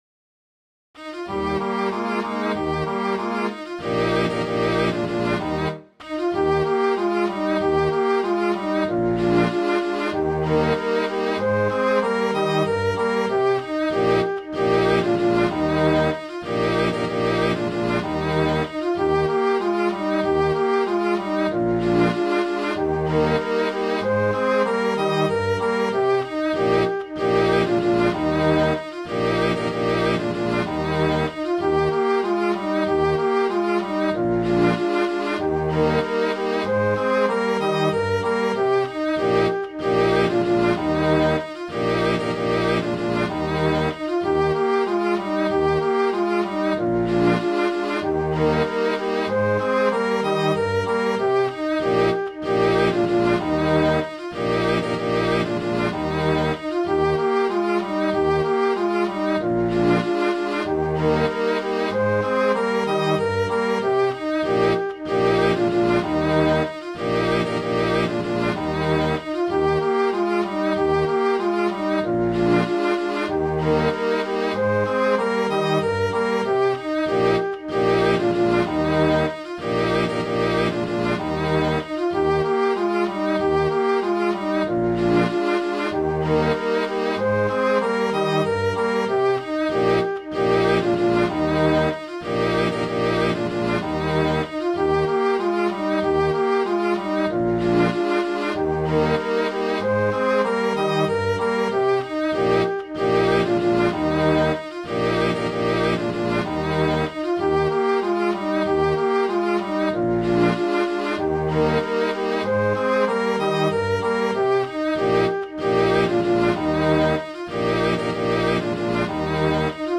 Midi File, Lyrics and Information to Leave Her, Johnny
leaveherj.mid.ogg